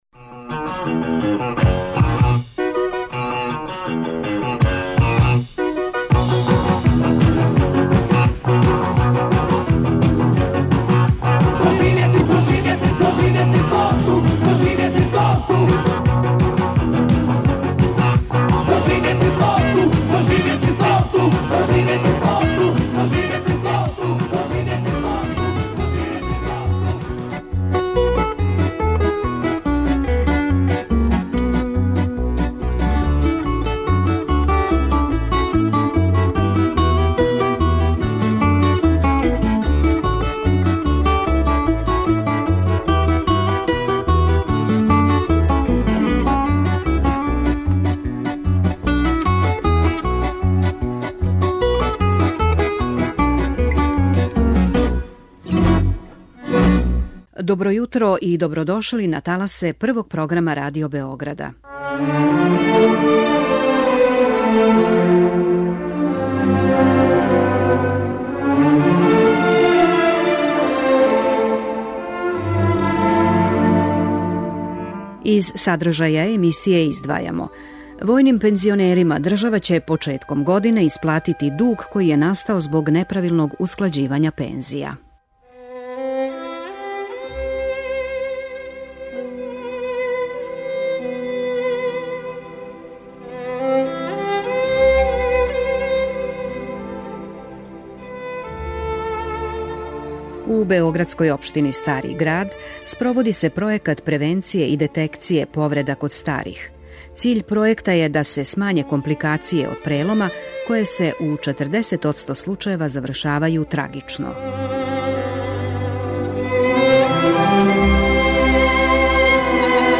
На проби, у студију шест Радио Београда, разговарали смо са једним од оснивача YU групе, Жиком Јелићем.